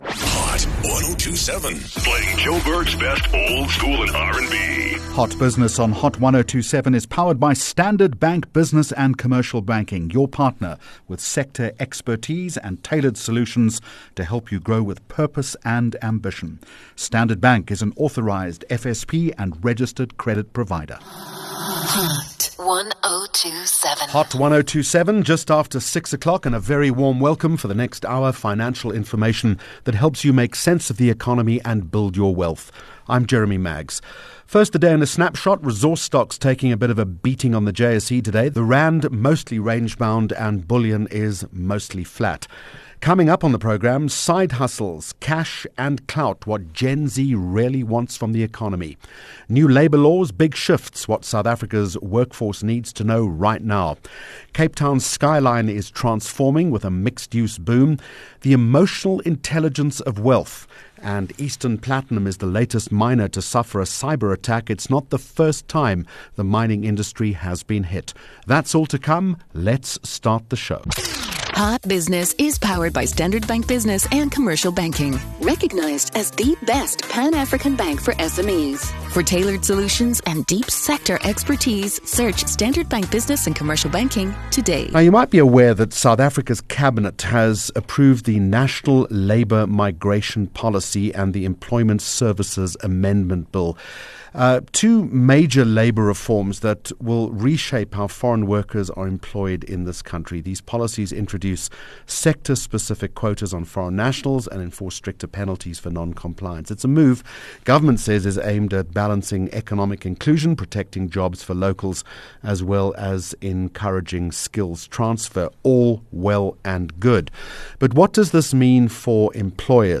Hot Business Interview